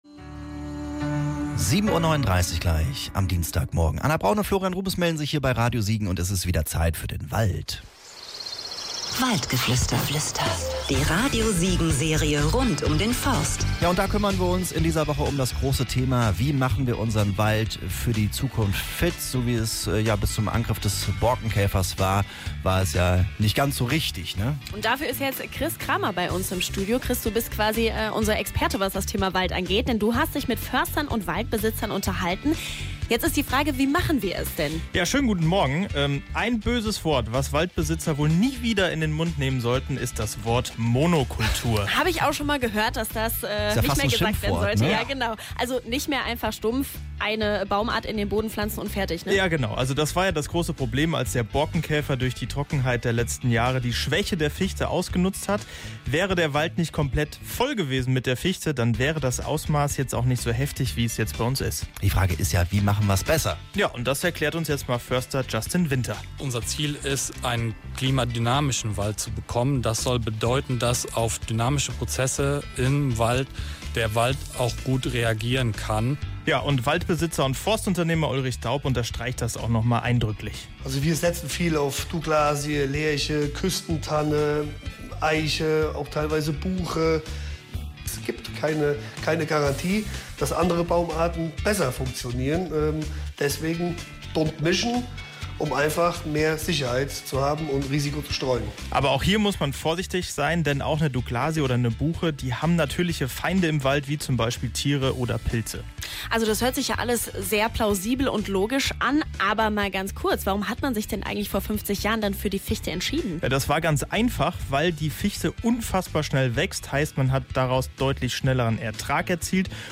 auf einen Spaziergang durch den Wald